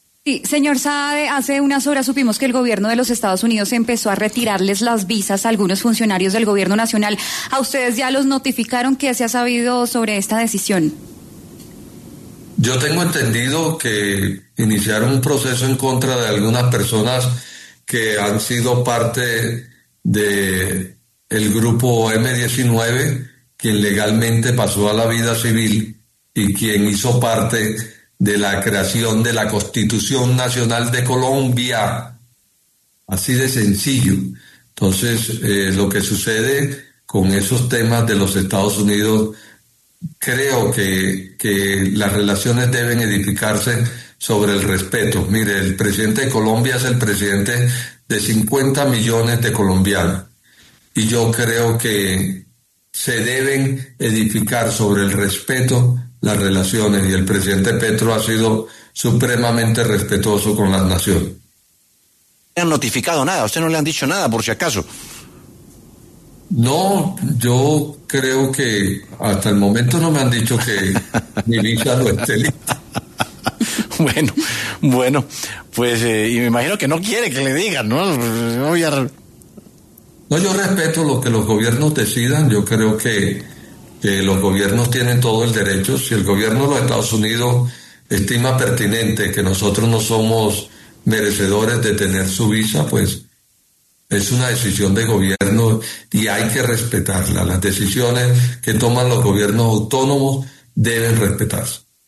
El jefe de Despacho de la Presidencia, Alfredo Saade, pasó por los micrófonos de W Sin Carreta para hablar sobre la medida del gobierno de Estados Unidos de retirarle las visas a algunos funcionarios del Gobierno Petro.